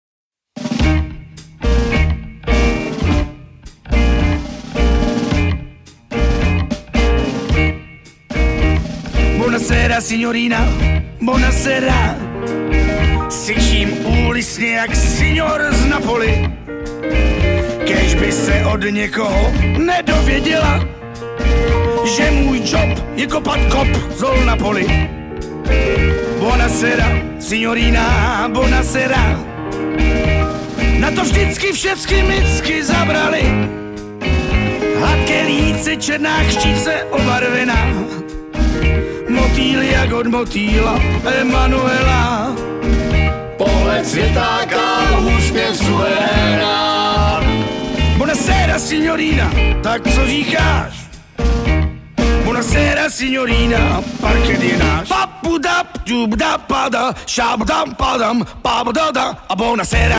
hosty na dechové nástroje.